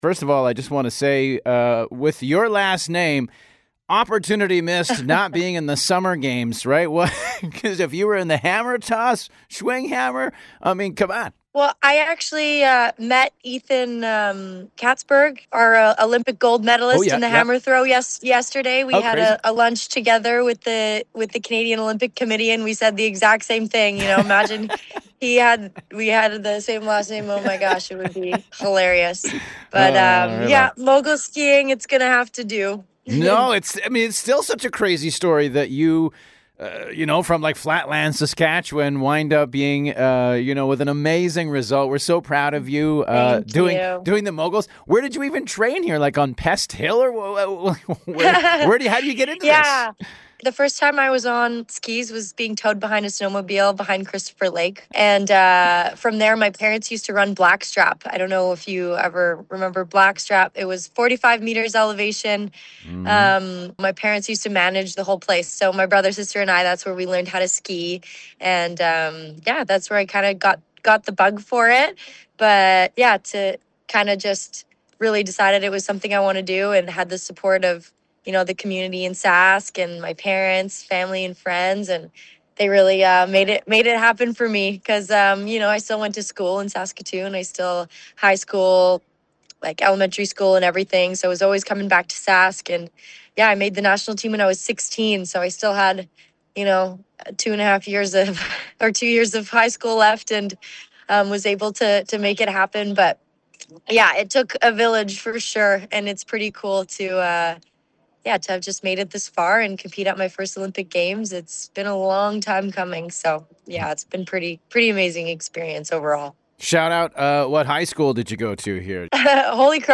chats one on one